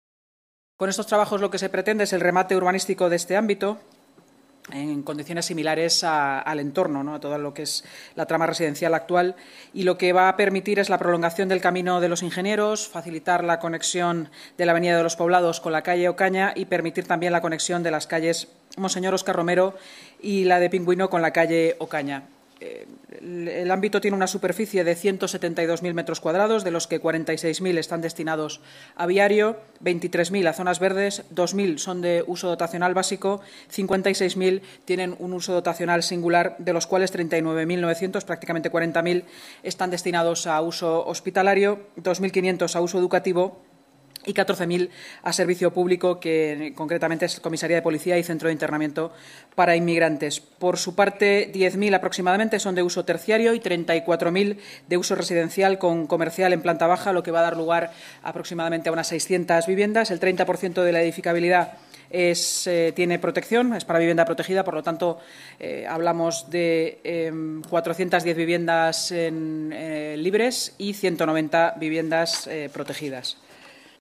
Nueva ventana:Intervención de la vicealcaldesa de Madrid, Inma Sanz, durante la rueda de prensa posterior a la Junta de Gobierno